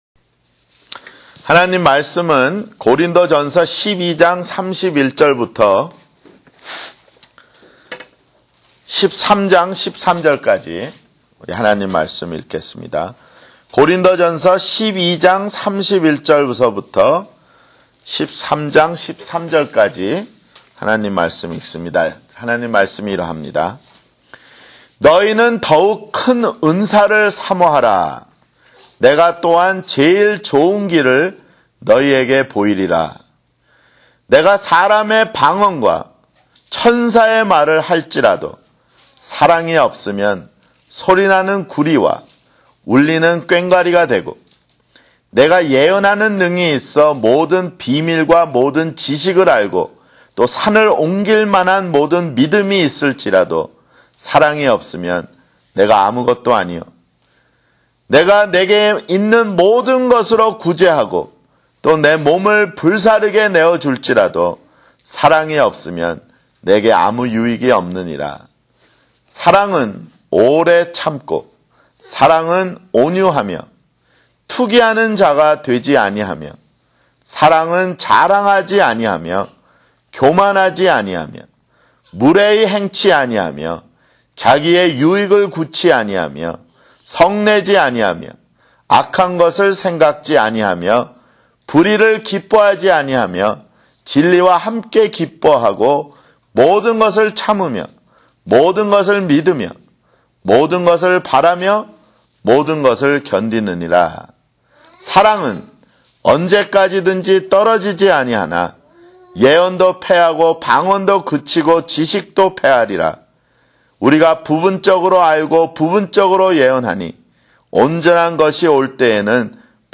MP3 다운로드 (좌측 링크에 오른쪽 마우스 클릭 후 "다른 이름으로 저장") Labels: 성경공부 - 수련회 (2007)